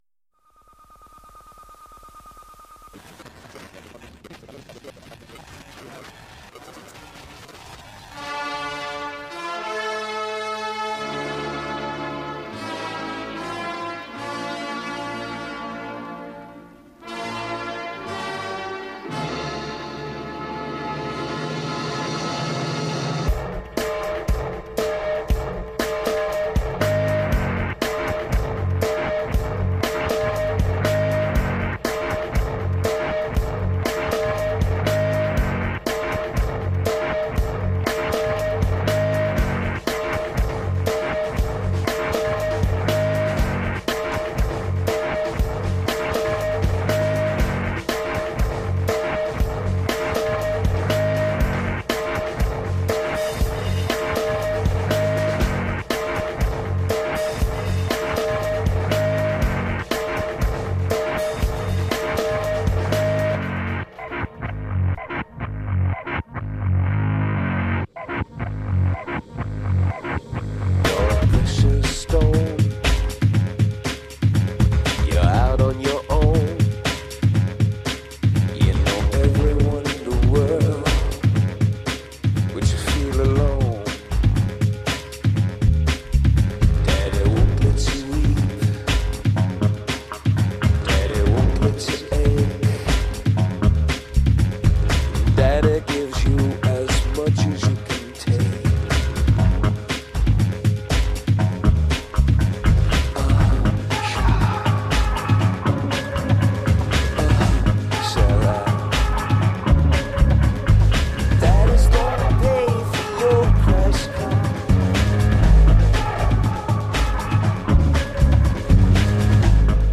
An office worker in Memphis, Tennessee plays music from his record collection, with a focus on post-punk, electronic music, dub, and disco.